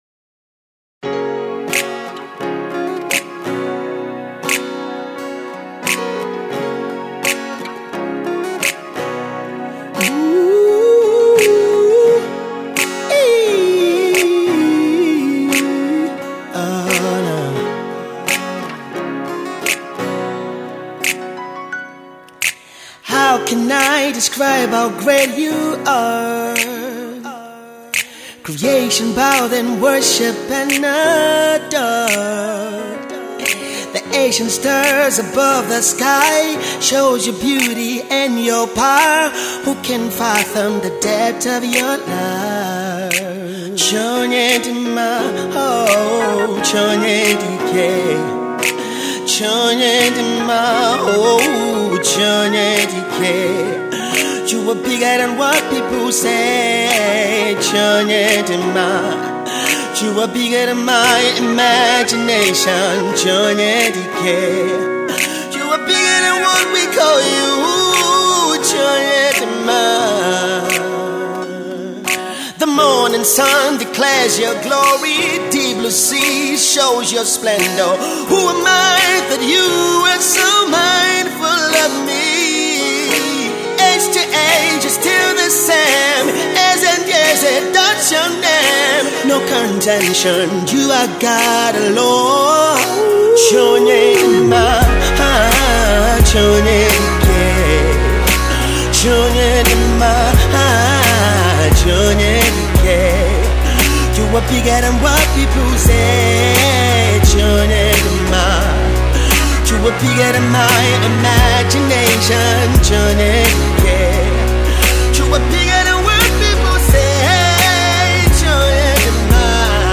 worship single
awesome vocal delivery